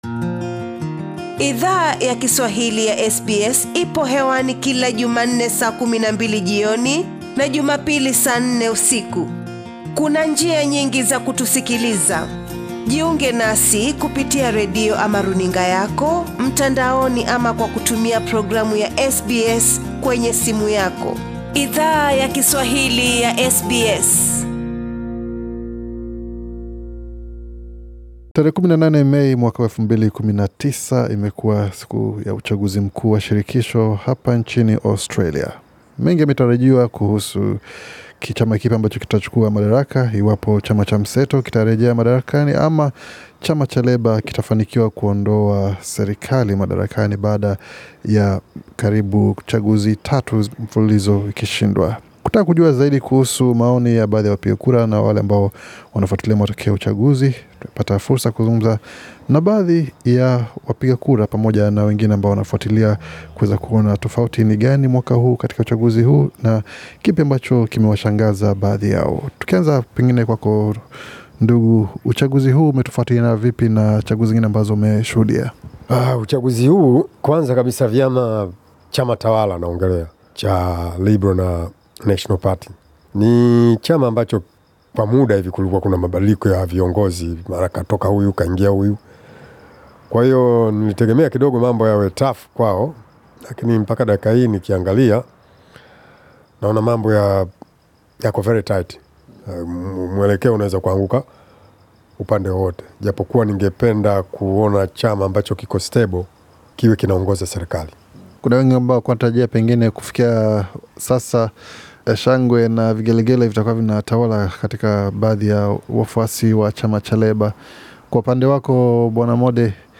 Hatahivyo matokeo ya uchaguzi mkuu wa shirikisho wa 2019, yame waacha wengi wakiwa na maswali chungu nzima kuliko majibu SBS Swahili ilizungumza na baadhi ya wapiga kura, kuhusu matokeo ya uchaguzi washirikisho wakihistoria wa 2019.